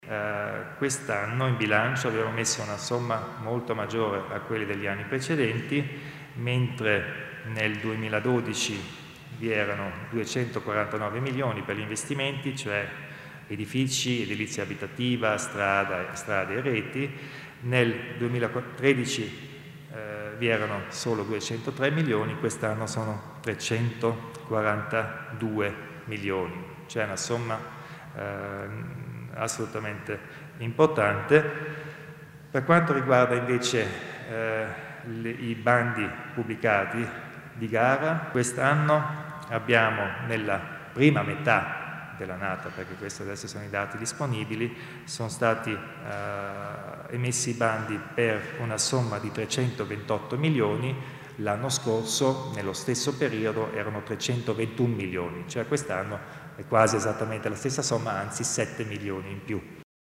Il Presidente Kompatscher illustra le cifre degli investimenti pubblici